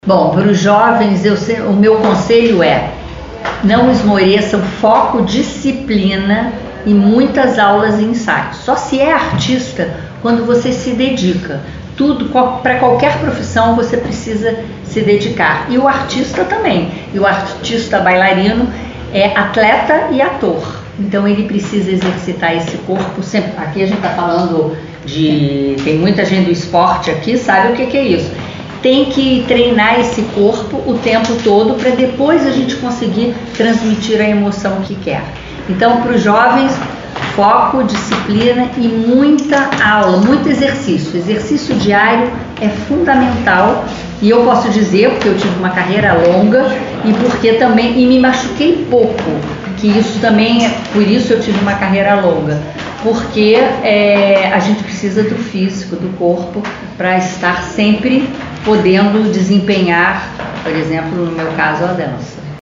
Muito simples e acessível, ela recebeu a imprensa para uma coletiva, na tarde de sexta-feira (21) e à noite, assistiu ao espetáculo ‘Como é grande o meu amor por São João’, realizado no CIC.
Ouçam o recado da bailarina.